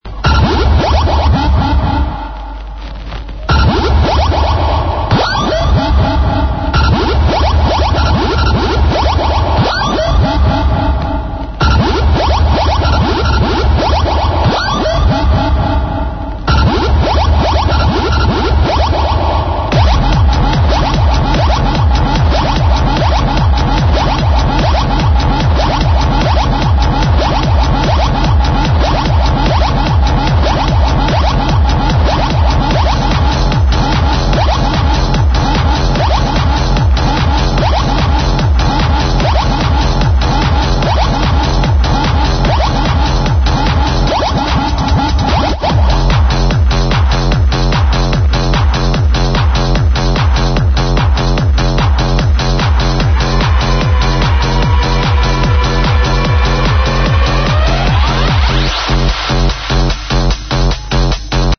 vocals incl "daddy" and "daddy, are you sure this is right?"